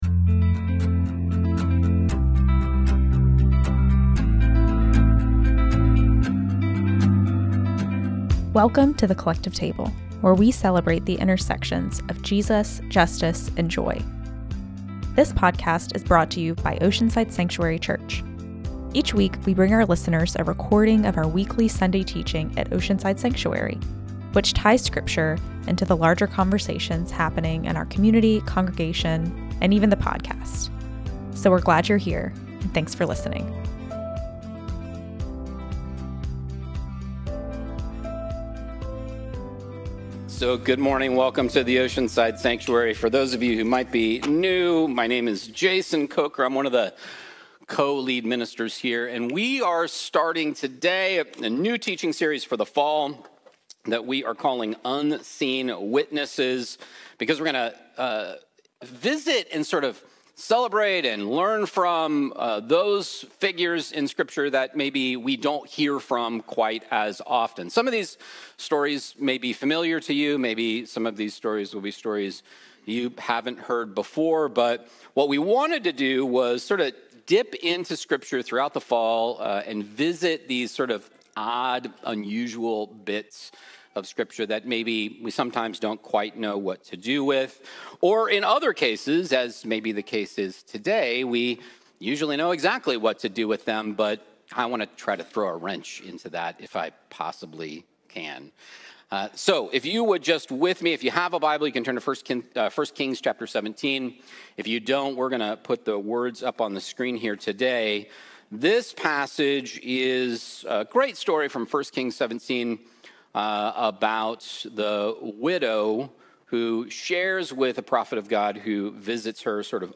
OSC Sunday Teaching - "A Little Cake" - September 7th, 2025